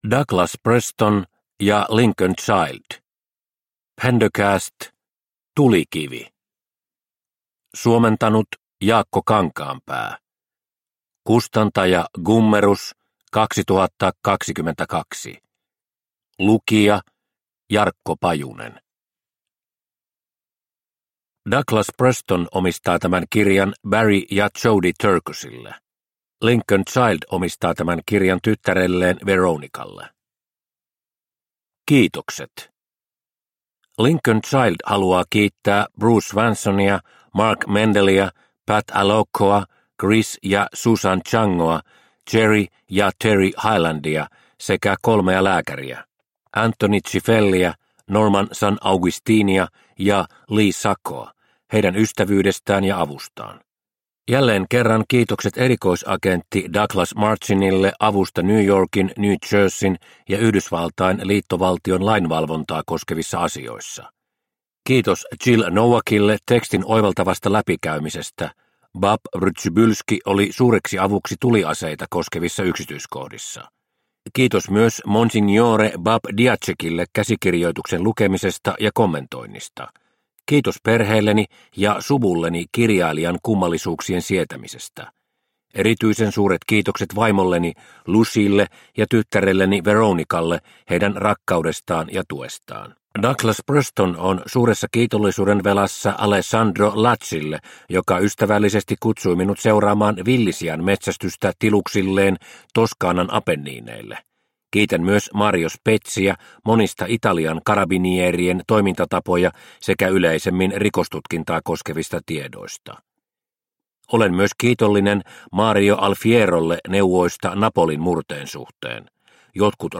Tulikivi – Ljudbok – Laddas ner